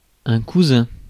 Ääntäminen
IPA: [ku.zɛ̃]